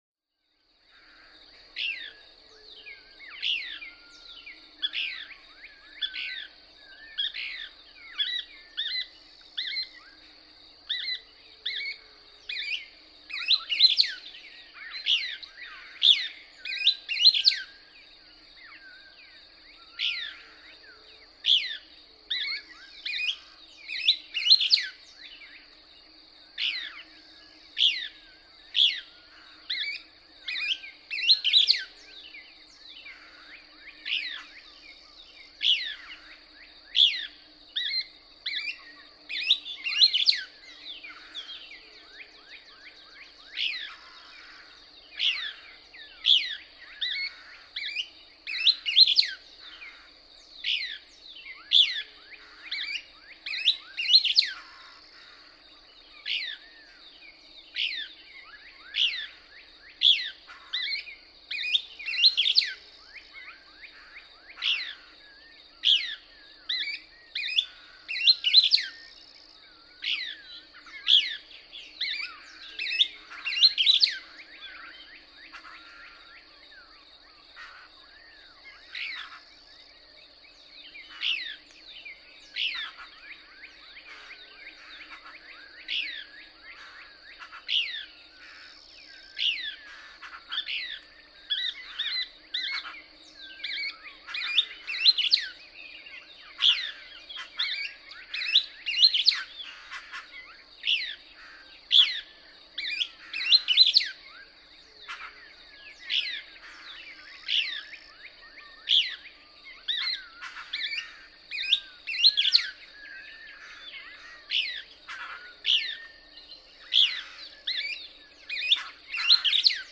♫614. Couch's kingbird: Dawn singing of the Couch's kingbird. Hear the stuttered phrase?
Chaparral Wildlife Management Area, Artesia, Texas.
614_Couch's_Kingbird.mp3